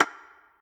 spinwheel_tick_08.ogg